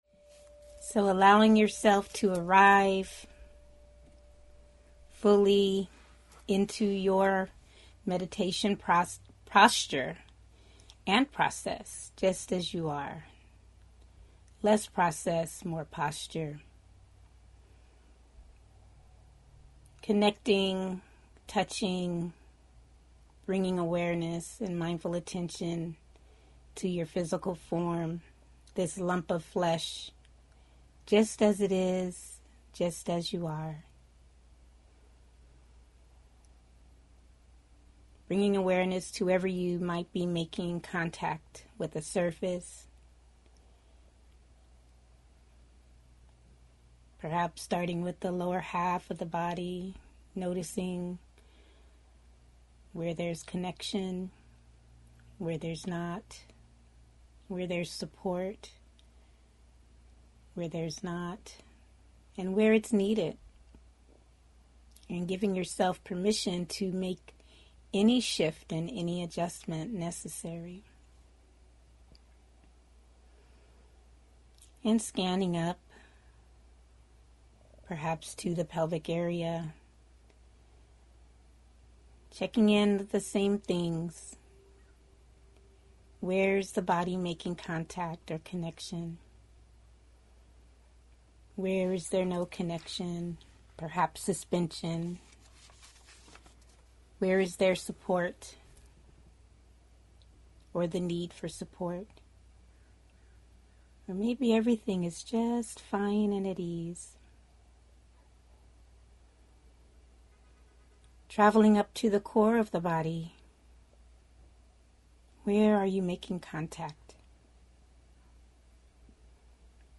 Guided Metta Meditation